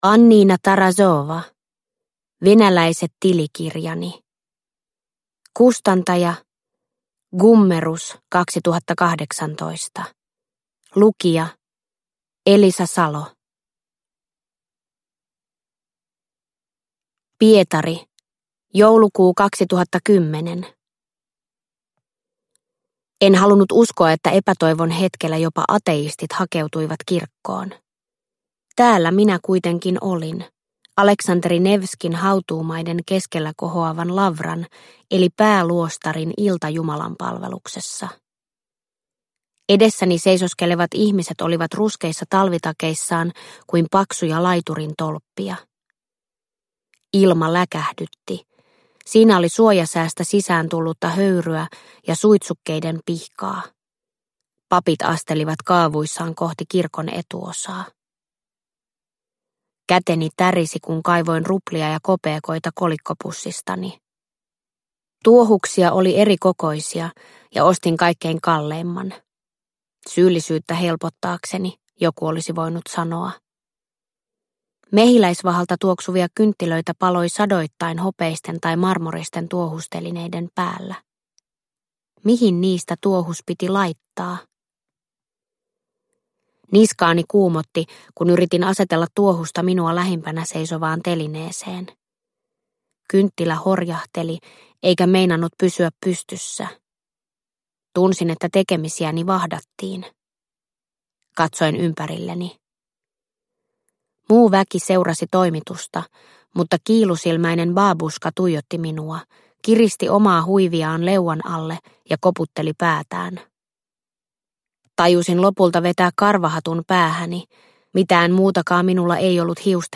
Venäläiset tilikirjani – Ljudbok – Laddas ner